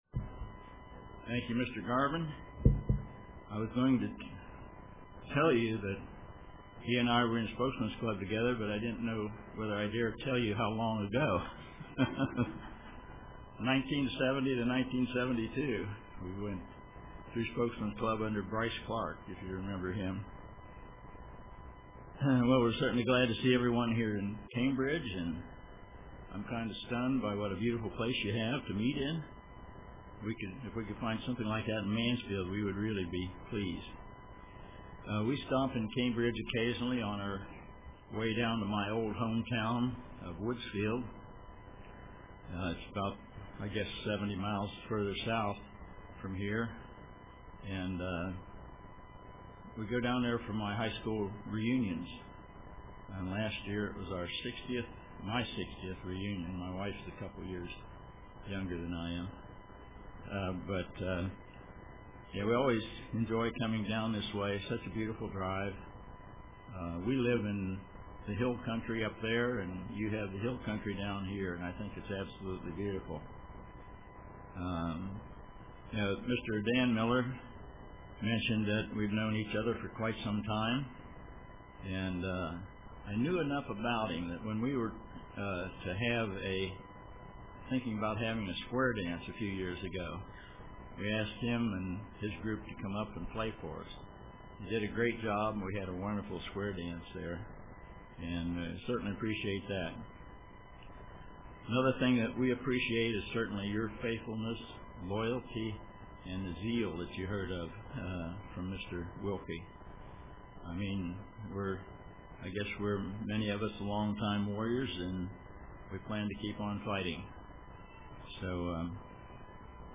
Print The fulfillment of the wavesheaf offering.The Purchase of a Chosen People UCG Sermon Studying the bible?